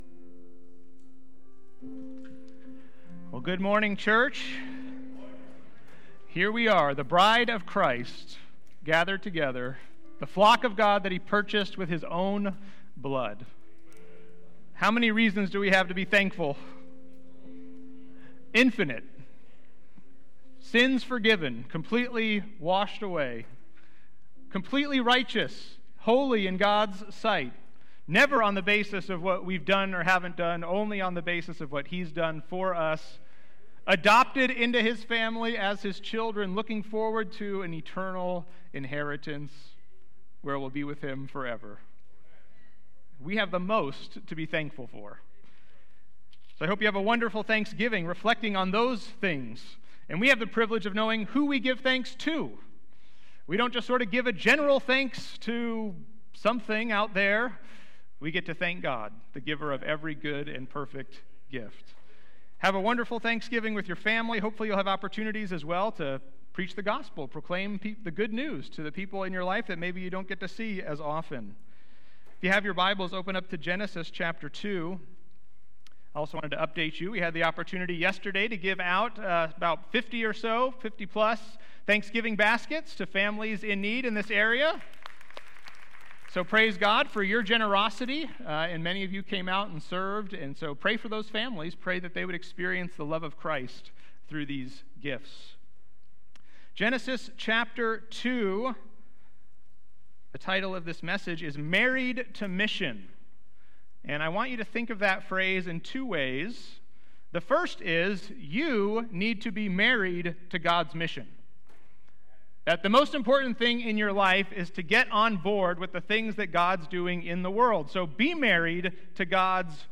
Valley Bible Church Sermons (VBC) - Hercules, CA